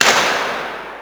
Akustik in der Aula der TU
Es gibt sehr viel Nachhall, wenn nur wenige Zuhörer im Saal sind.
Aus dem zeitlichen Verlauf der Frequenzananlyse ergibt sich für die hohen Frequenzen ein schnellerer Abfall als für die tiefen.
Beim Versuch wurde der Knall eines Luftgewehres genutzt, daß auch ohne Geschoß einen zeitlich kurzen aber kräftigen Schallimpuls auslöst, bei dem ein breites Band mit sehr vielen Frequenzen entsteht.
Zur Aufnahme wurde ein hochwertiger MiniDisc- Walkman von Sony  benutzt, dessen automatische Lautstärkenregelung abgeschaltet war.
Schuß mit Luftgewehr (ohne Projektil)